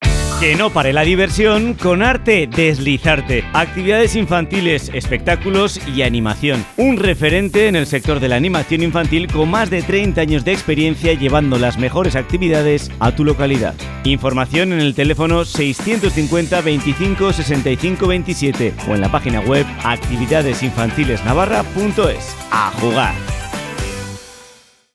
Publicidad_Cadena_100-copia.mp3